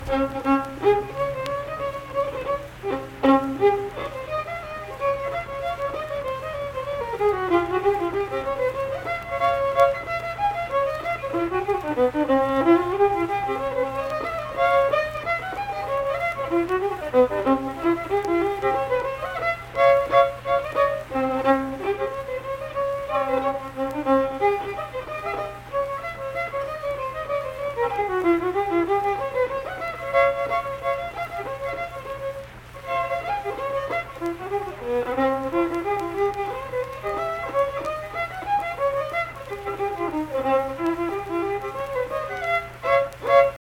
Unaccompanied vocal and fiddle music
Instrumental Music
Fiddle